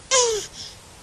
PLAY gemidos de tortuga
tortuga-gemidos.mp3